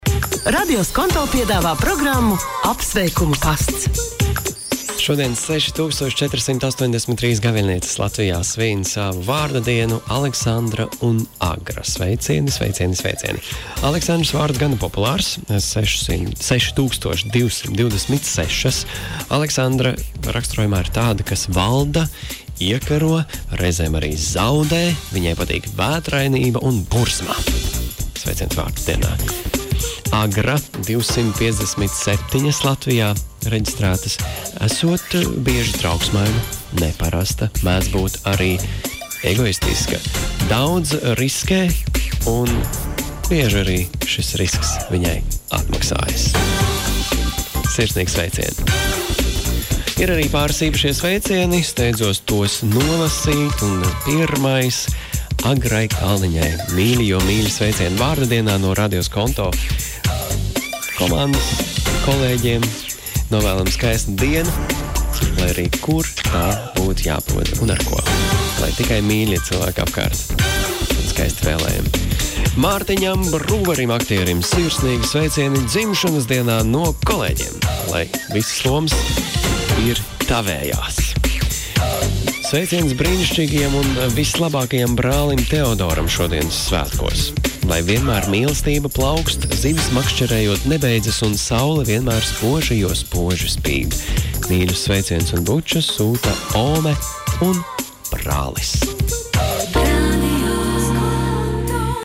RADIO SKONTO sveic savus klausītājus katru darba dienu ap plkst. 15.30 un sestdienās ap plkst. 12.30.